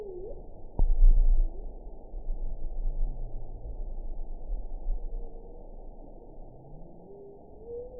event 922110 date 12/26/24 time 12:18:44 GMT (5 months, 3 weeks ago) score 8.54 location TSS-AB10 detected by nrw target species NRW annotations +NRW Spectrogram: Frequency (kHz) vs. Time (s) audio not available .wav